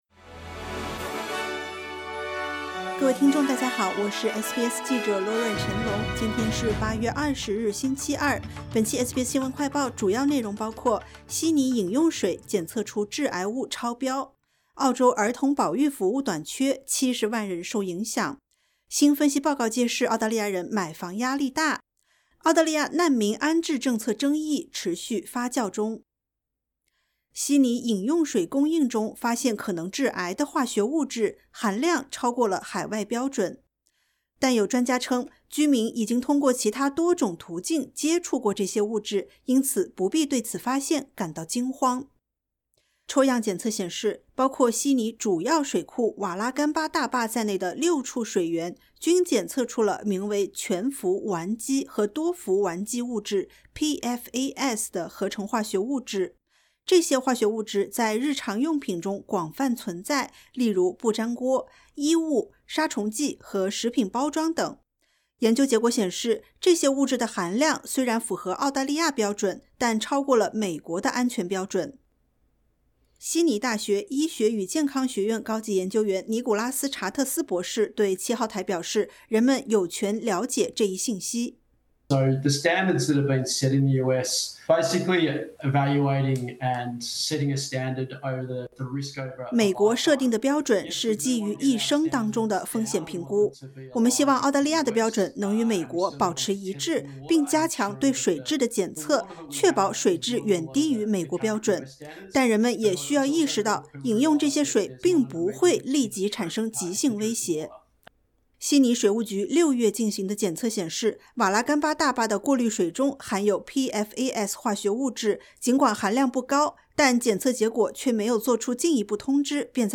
【SBS新闻快报】悉尼饮用水检测出致癌物超标